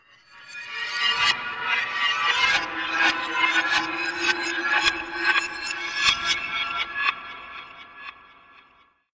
Звуки реверса